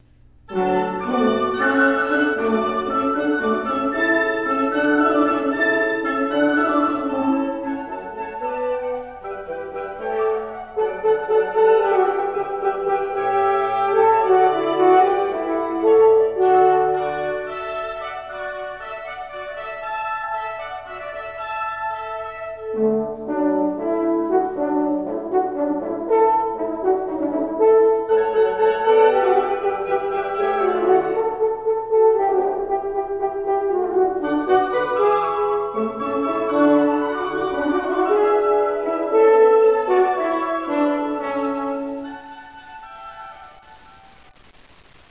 Popular prelude and ceremony music for weddings